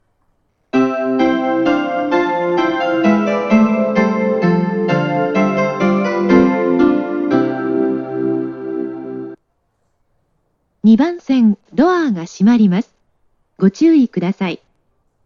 接近放送 「朝つゆ」です。
●音質：良